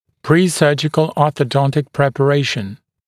[ˌpriː’sɜːʤɪkl ˌɔːθə’dɔntɪk ˌprepə’reɪʃn][ˌпри:’сё:джикл ˌо:сэ’донтик ˌпрэпэ’рэйшн]дохирургическая ортодонтическая подготовка